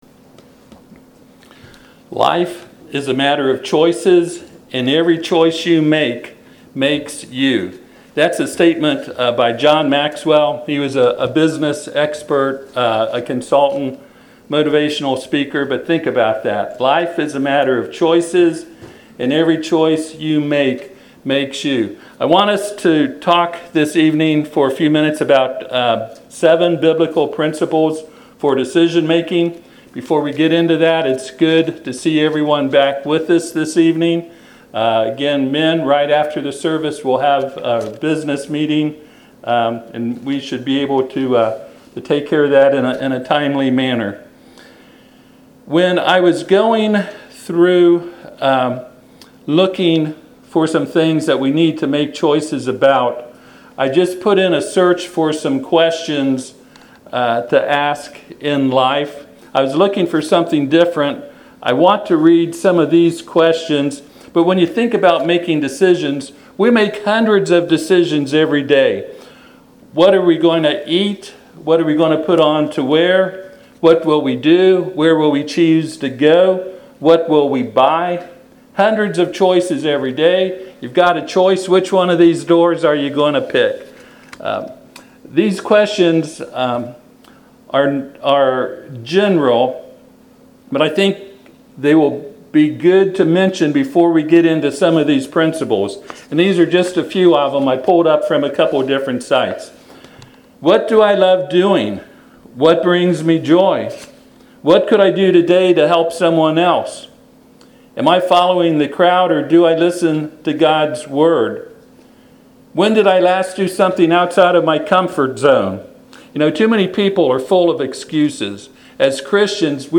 Deuteronomy 30:14-20 Service Type: Sunday PM « Revelation